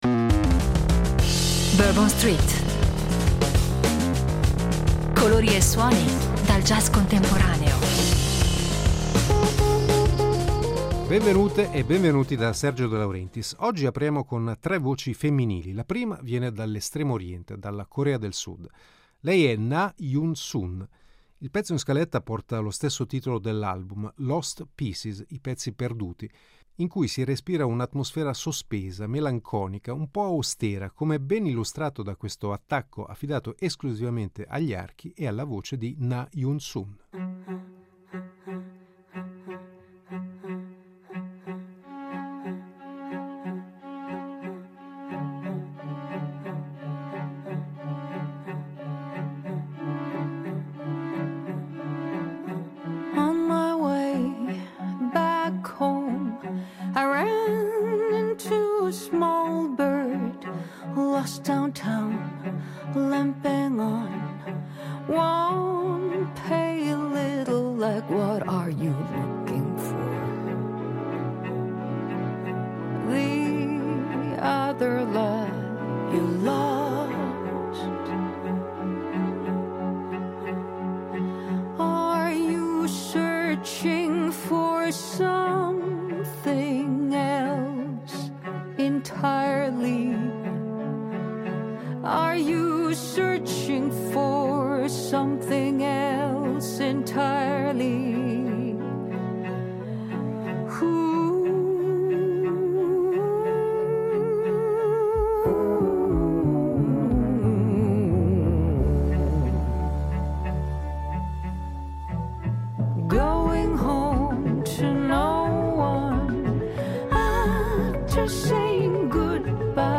Tra delicatezza vocale e virtuosismi strumentali
jazz